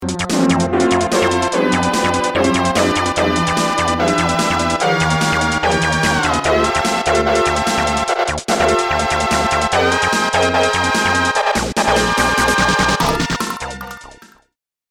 Fade-out added
Fair use music sample